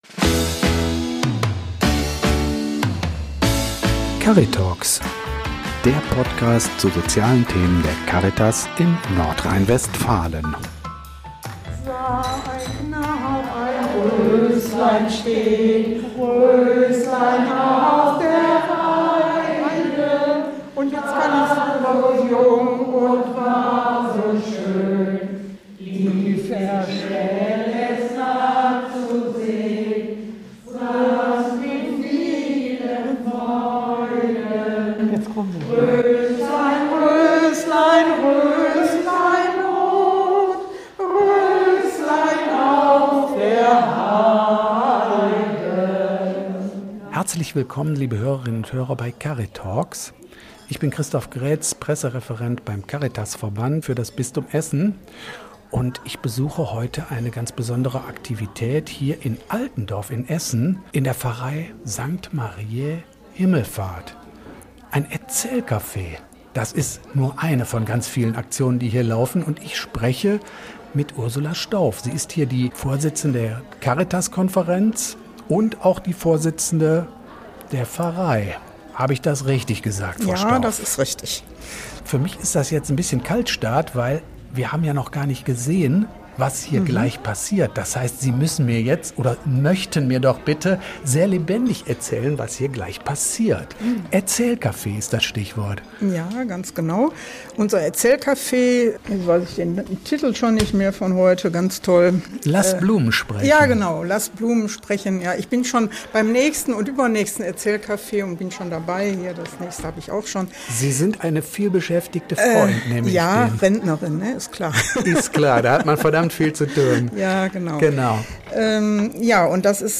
Während Kaffee und kalte Getränke ausgeschenkt werden, entwickeln sich an den Tischen angeregte Gespräche und ein ordentlicher Lärmpegel.